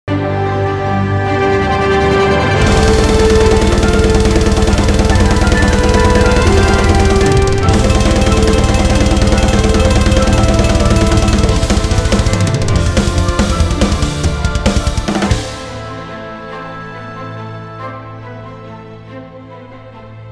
[Orchestra] Carol of the Bells / Щедрик
Вложения Carol Of The Bells + Drums.mp3 Carol Of The Bells + Drums.mp3 793,9 KB · Просмотры: 737